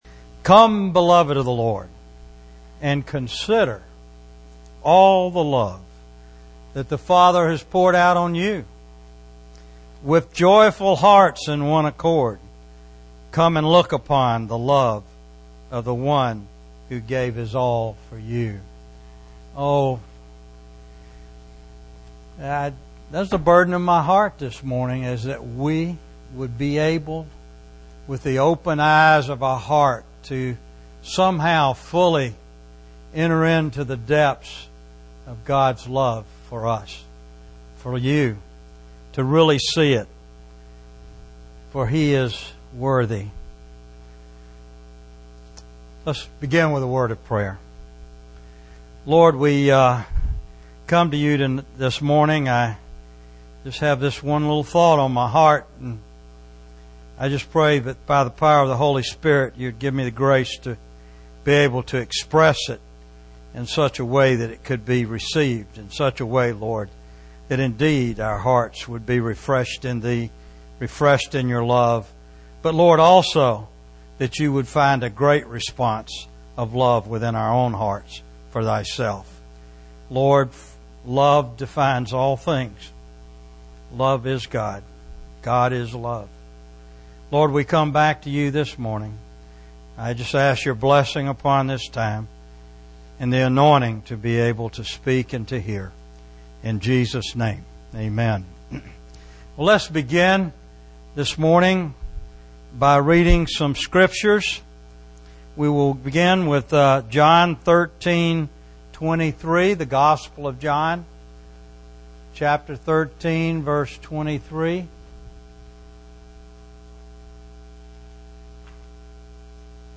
A collection of Christ focused messages published by the Christian Testimony Ministry in Richmond, VA.
Richmond, Virginia, US